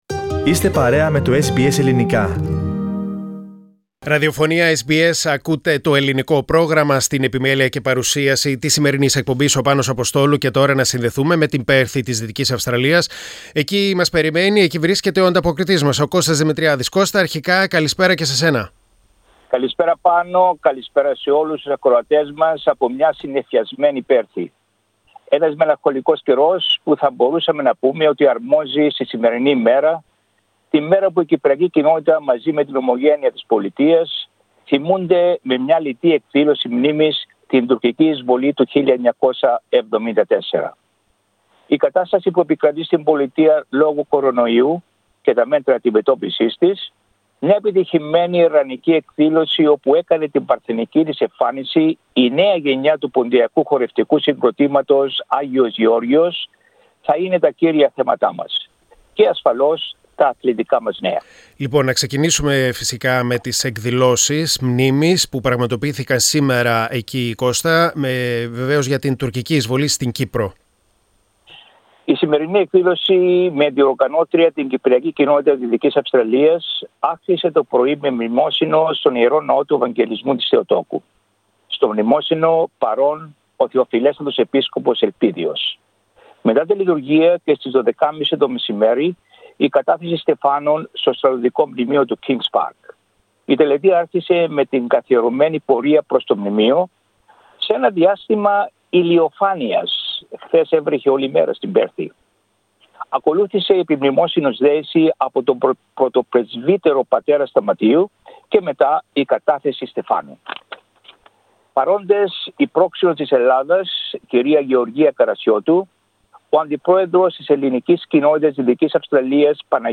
Ειδήσεις και νέα από την ομογένεια της Δυτικής Αυστραλίας στην εβδομαδιαία ανταπόκριση από την Πέρθη.